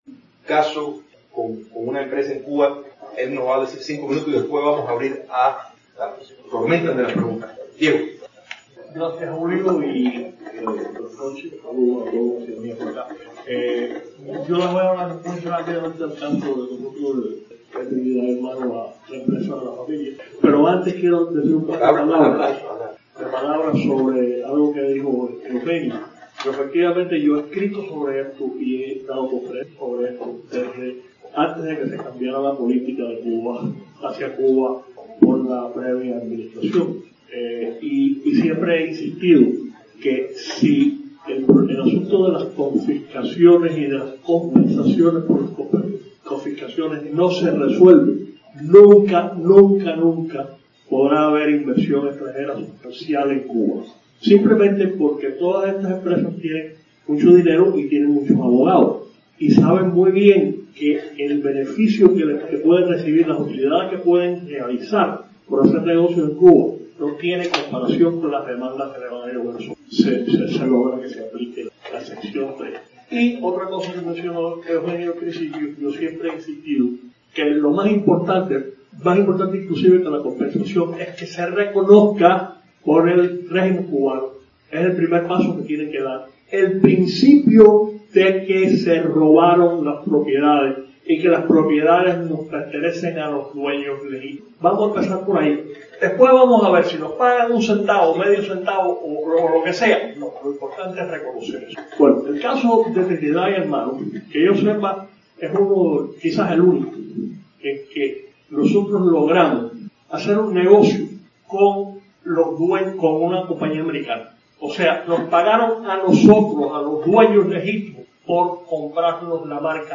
Audios de programas televisivos, radiales y conferencias